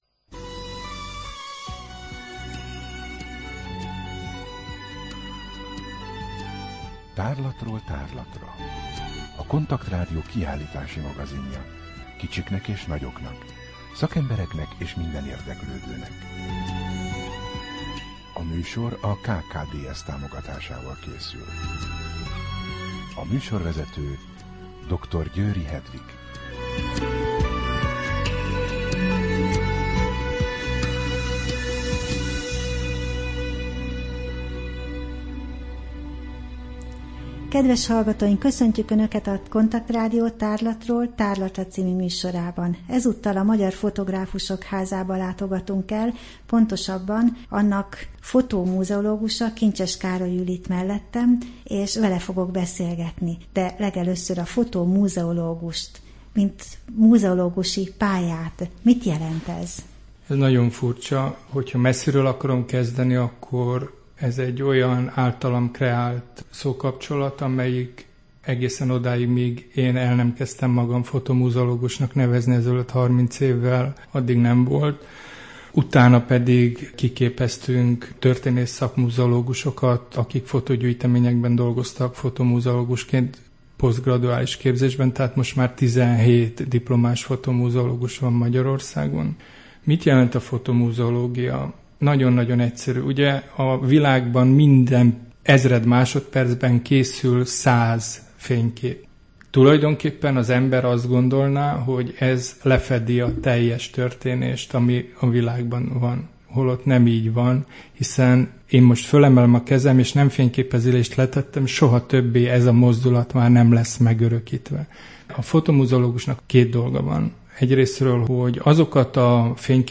Rádió: Tárlatról tárlatra Adás dátuma: 2013, August 26 Tárlatról tárlatra / KONTAKT Rádió (87,6 MHz) 2013. augusztus 26. A műsor felépítése: I. Kaleidoszkóp / kiállítási hírek II. Bemutatjuk / Kreált és talált tájak, Magyar Fotográfusok Háza Vendégünk